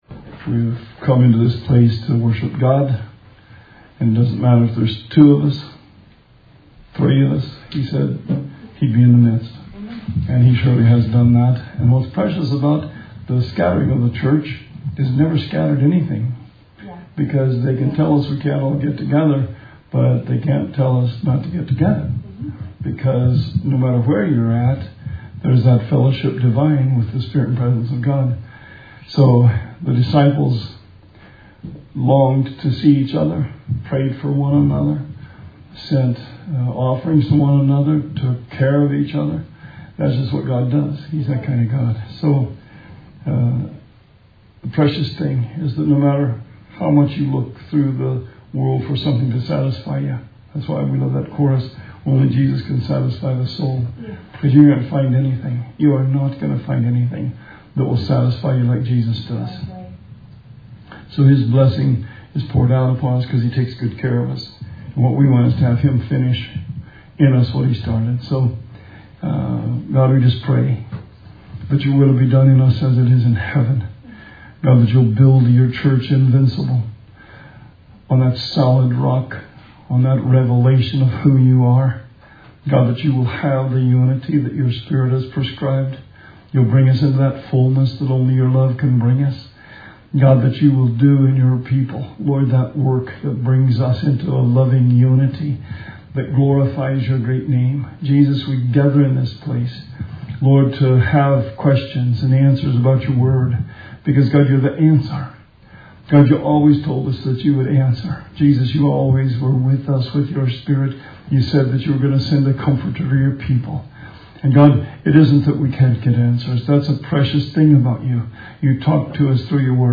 Bible Study 5/20/20